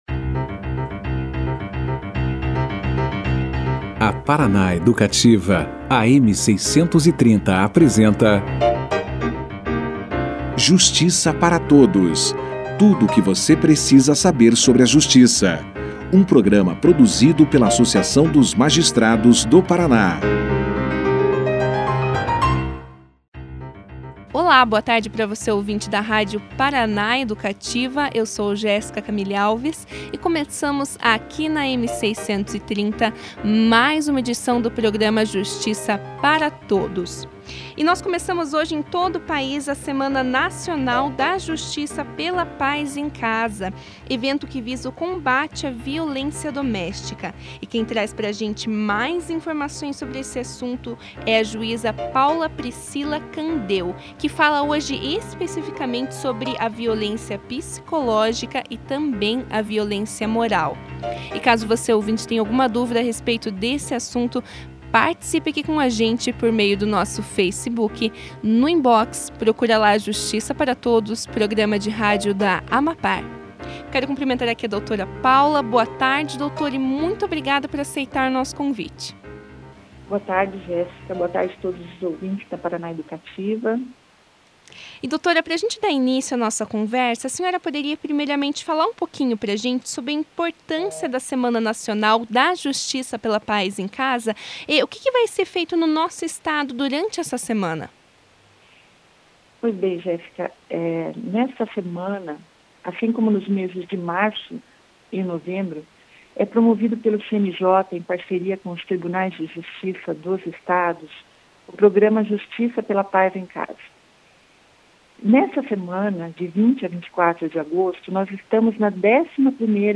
Quem trouxe mais informações e esclarecimentos a respeito do assunto foi a juíza Paula Priscila Candeo. A importância do projeto no combate a violência doméstica, o conceito de violência psicológica e moral e o que sãos medidas protetivas foram questões abordadas pela magistrada durante a entrevista.